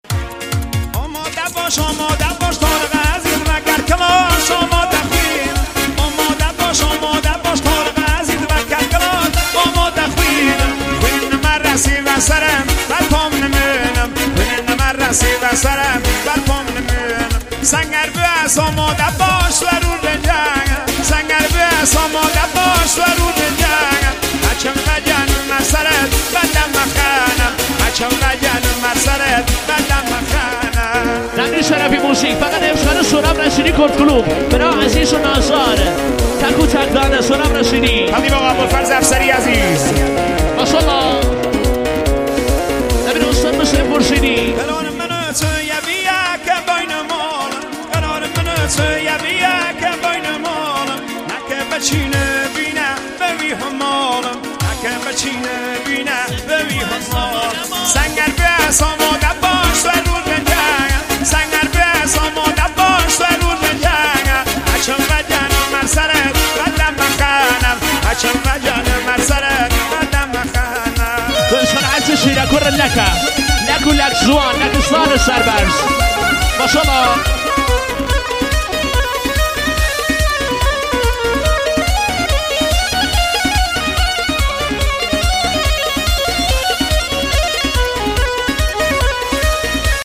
موزیک کردی